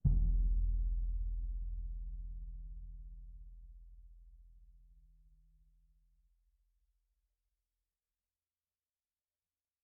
bassdrum_hit_mp1.mp3